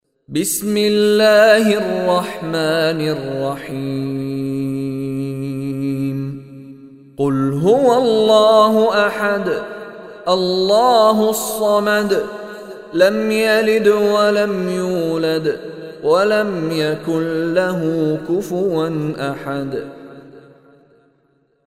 Surah Ikhlas MP3 Recitation by Mishary Rashid
Surah Ikhlas is 112 chapter / surah of Holy Quran. Listen online and download beautiful recitation of Surah Ikhlas in the voice of Sheikh Mishary Rashid Alafasy.